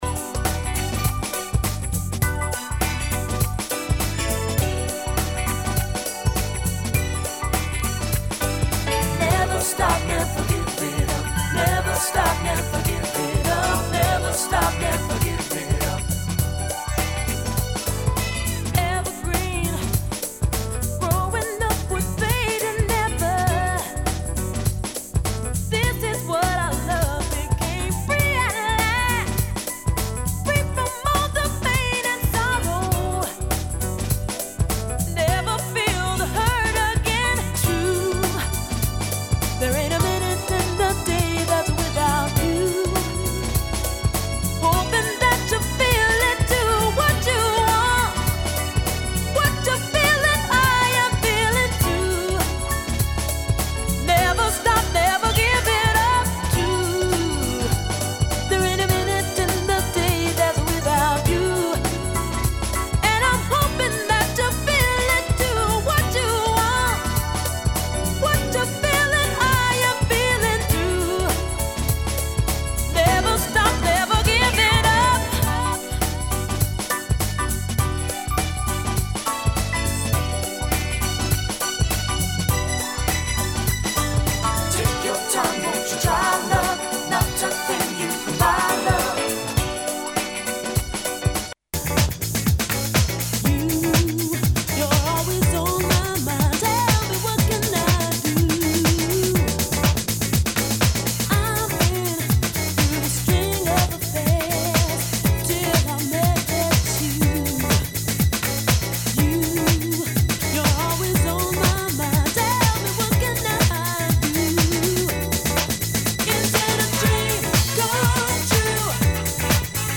Downtempo-Jazz